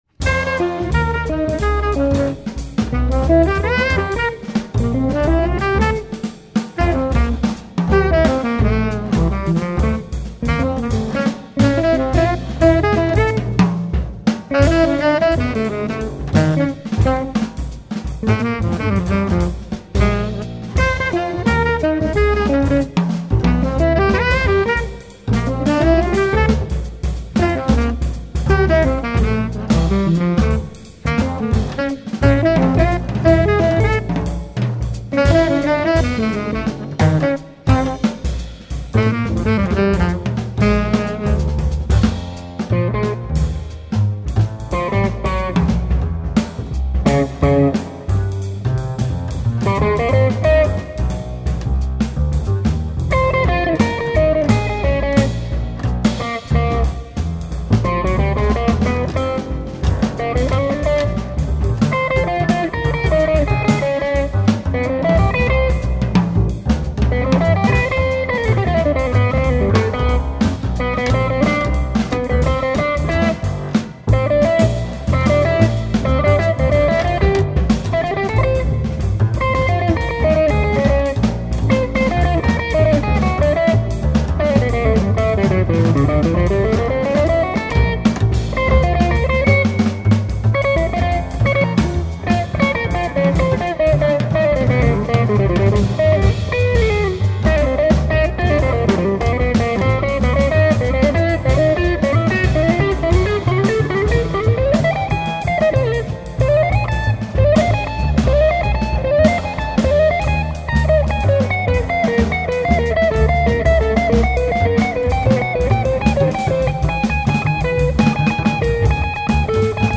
tenor sax
guitar
bass
drums